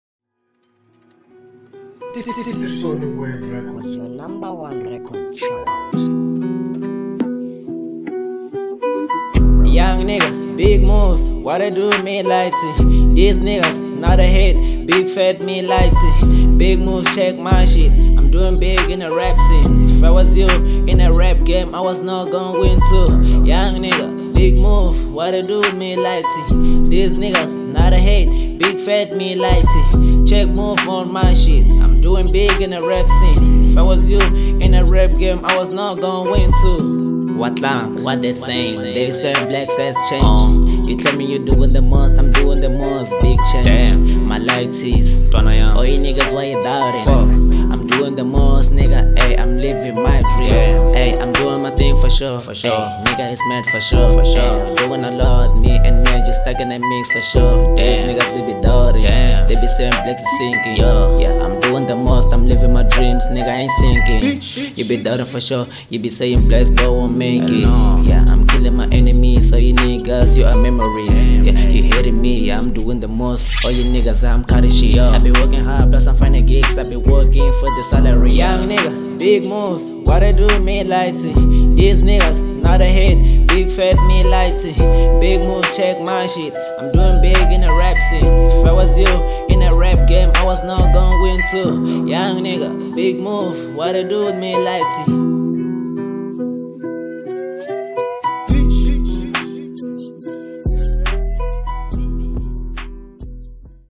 01:43 Genre : Trap Size